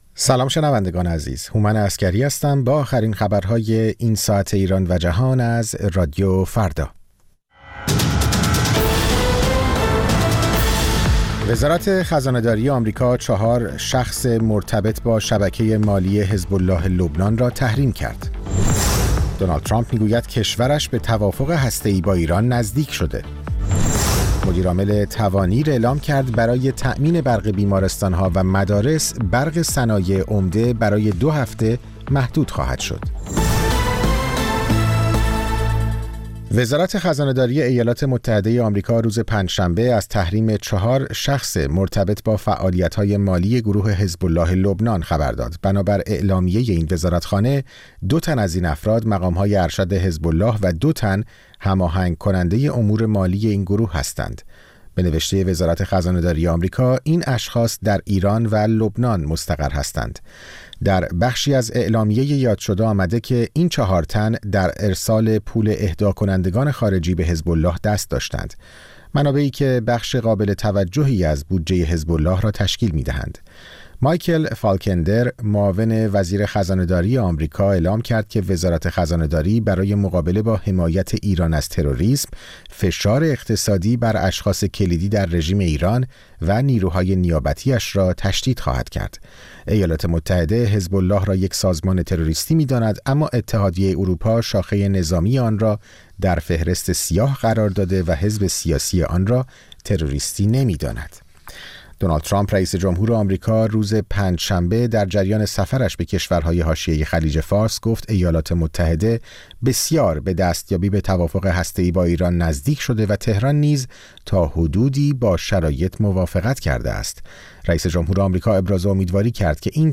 پخش زنده - پخش رادیویی